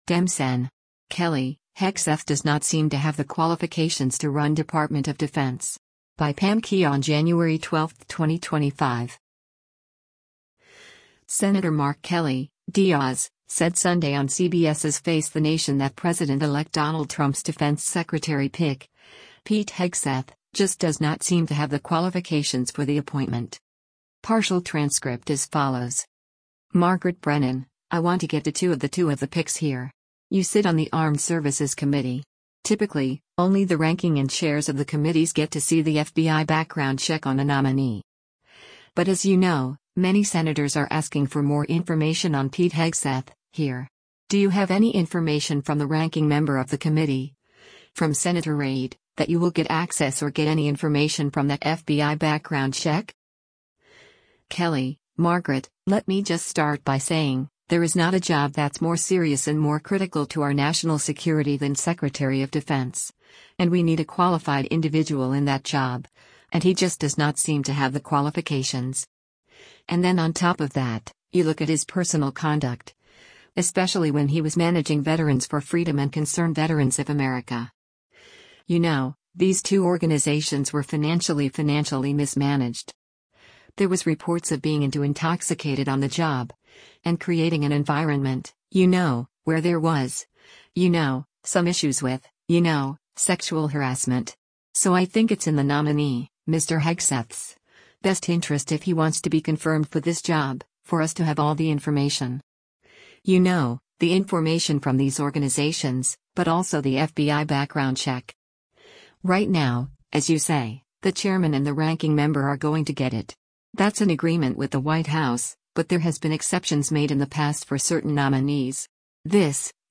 Senator Mark Kelly (D-AZ) said Sunday on CBS’s “Face theNation” that President-elect Donald Trump’s defense secretary pick, Pete Hegseth, “just does not seem to have the qualifications” for the appointment.